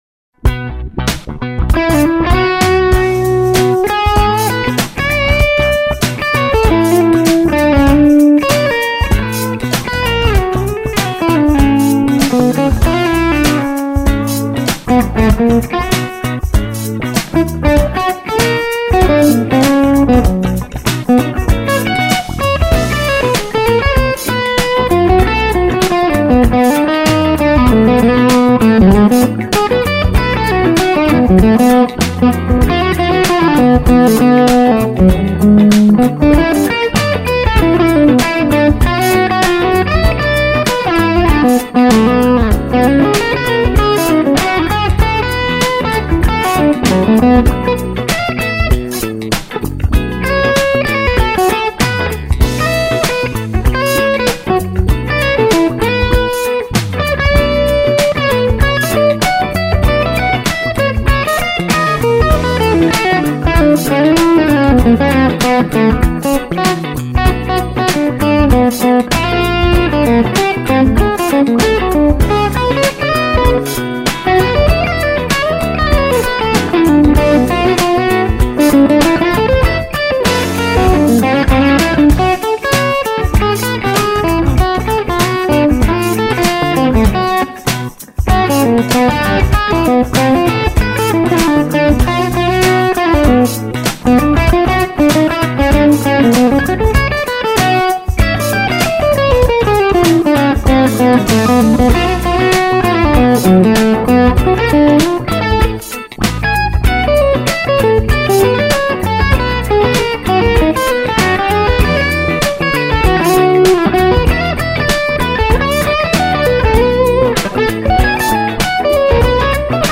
These clips are the EMU built in mic pre so no issues. SM57 mic and G1265 speaker.
Standard feedback and presence.
I swap the OD tube from EH to RFT at the 2:31 mark. Gain a little lower than the other clips. Mic was probably an inch or so to the edge more here as well.
Better edge and more harmonics.